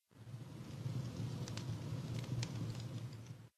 Minecraft Version Minecraft Version latest Latest Release | Latest Snapshot latest / assets / minecraft / sounds / block / campfire / crackle2.ogg Compare With Compare With Latest Release | Latest Snapshot
crackle2.ogg